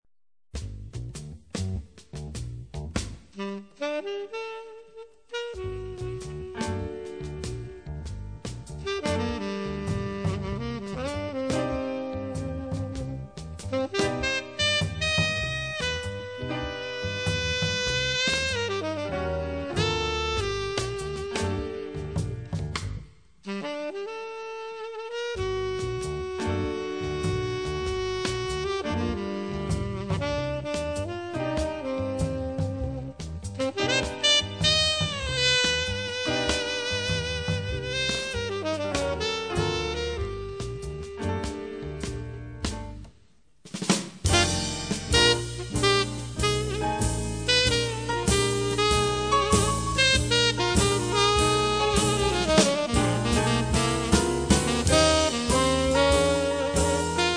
servies par un son d'une extrême douceur.
Sax ténor